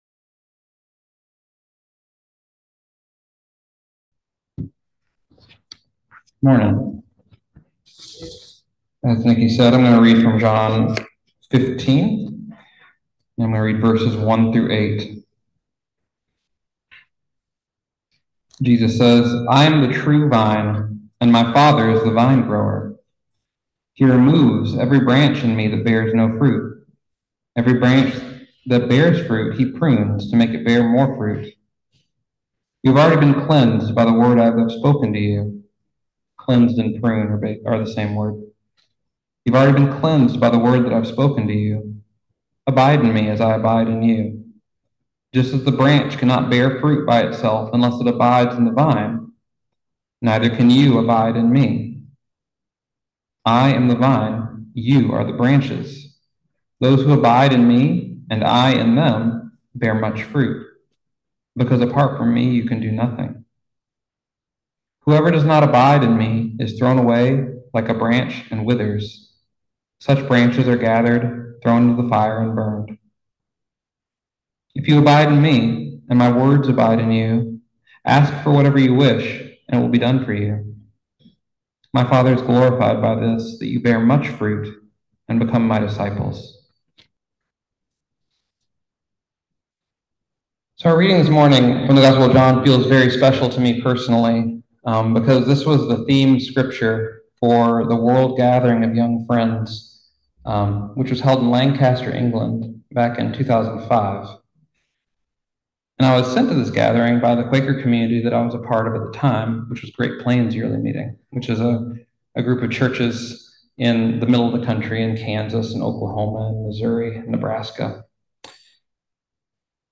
Message for April 28, 2024